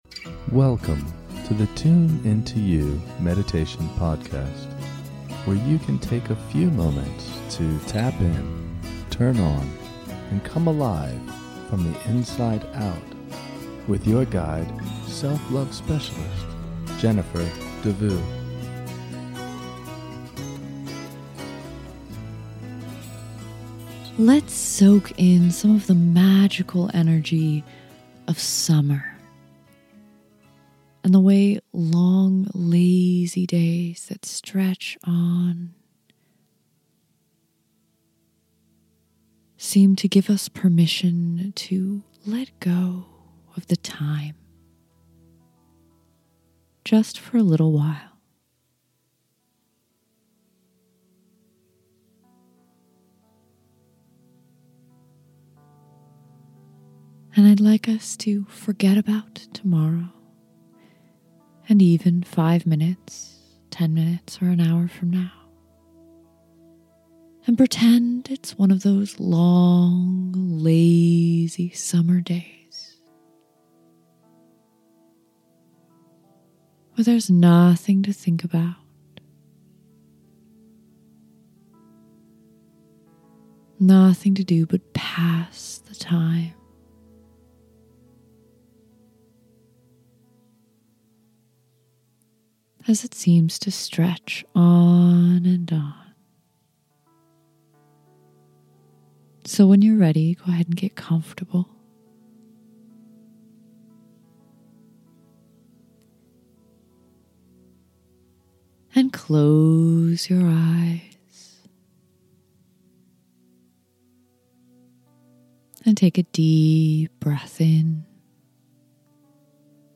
In this short guided meditation, you can release all concerns for a few minutes as you drift down a relaxing river with me.